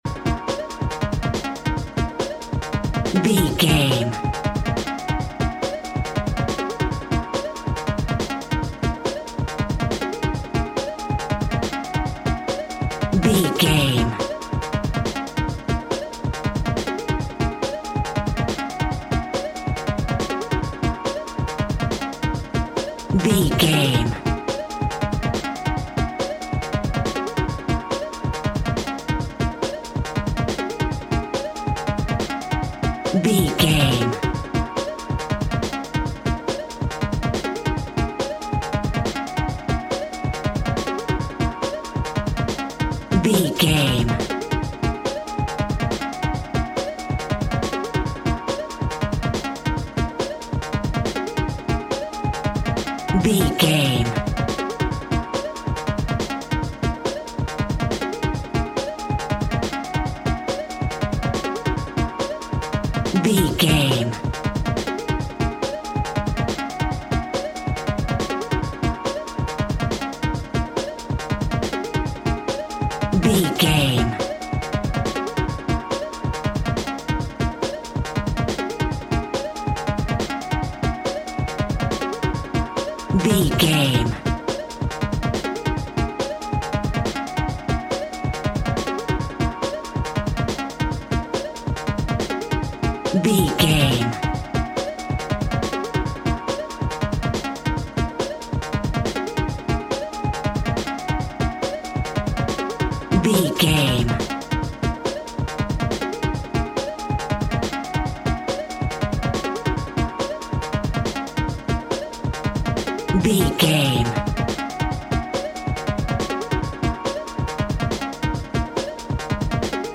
Upbeat Electro Music.
Aeolian/Minor
E♭
energetic
uplifting
futuristic
hypnotic
Drum and bass
break beat
sub bass
techno
synth drums
synth leads
synth bass